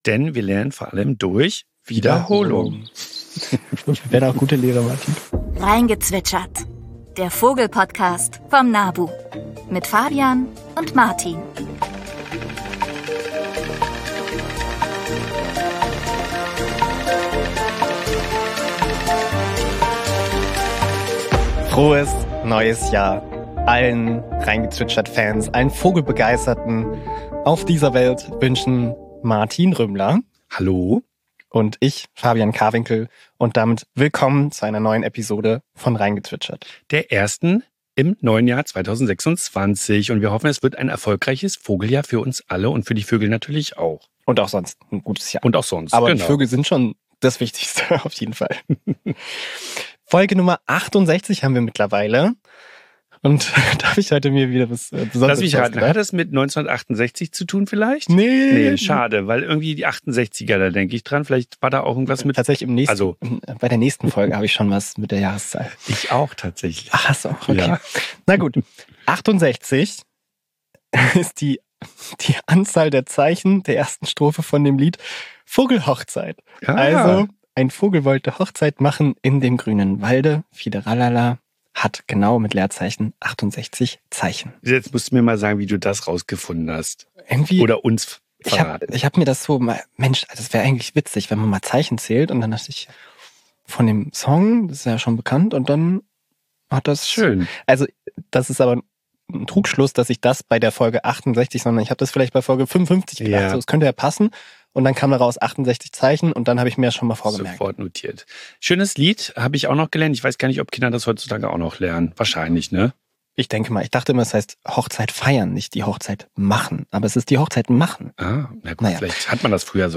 Winter ist keineswegs still: Auch jetzt zwitschert und ruft es überall. Aber wie erkennt man Wintergäste am Klang? Wie unterscheiden sich Berg- und Buchfink oder die verschiedenen Zeisige?